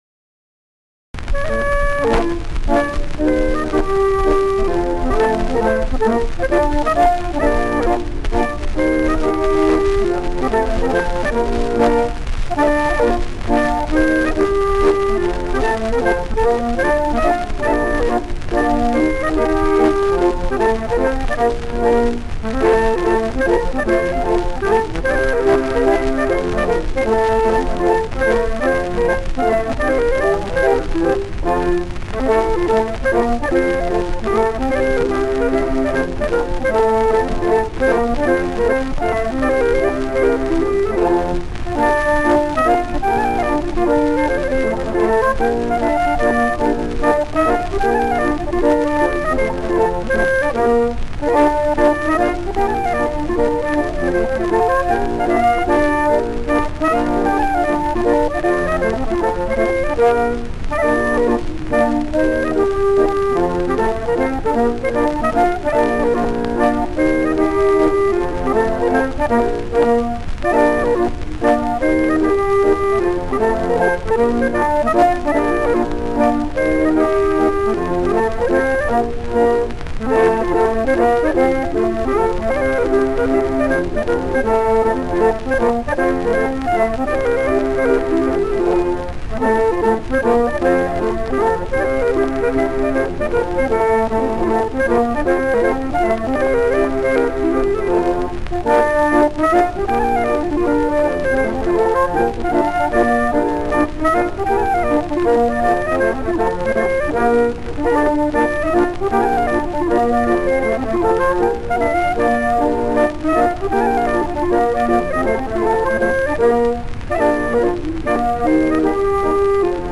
Folkmusik och spelmän i Västmanland
Danslåt (mp3)